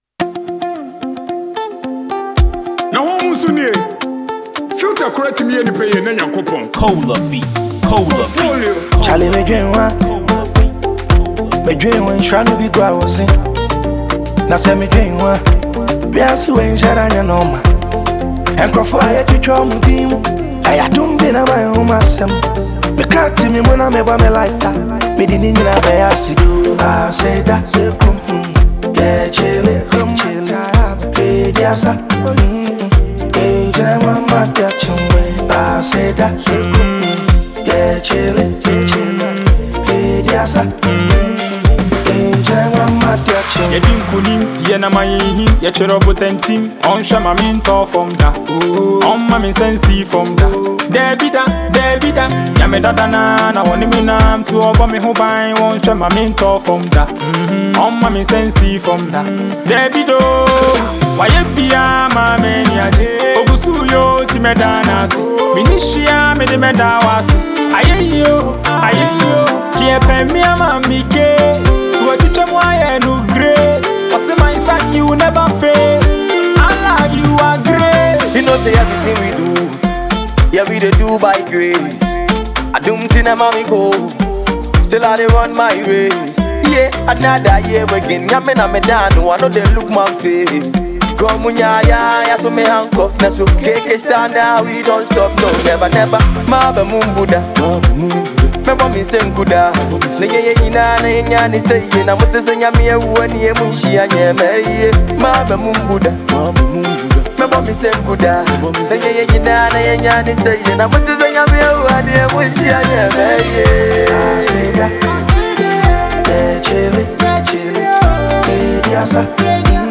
rapper